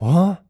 Male_Grunt_Curious_02.wav